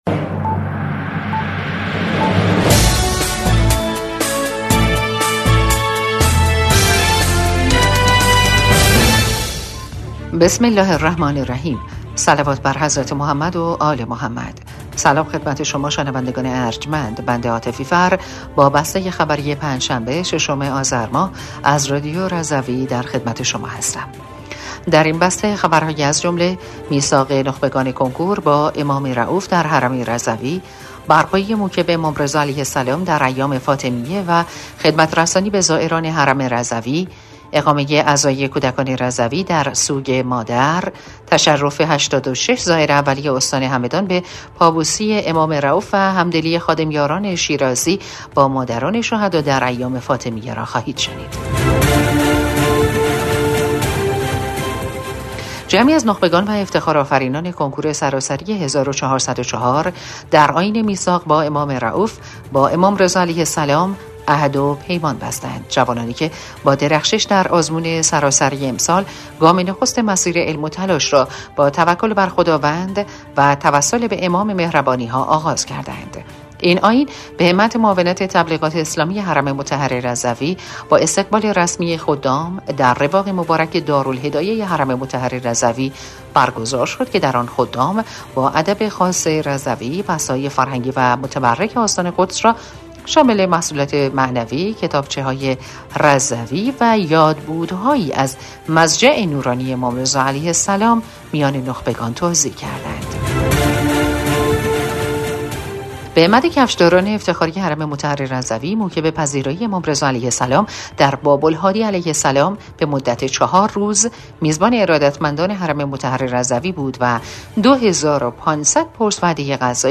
بسته خبری ۶ آذر ۱۴۰۴ رادیو رضوی؛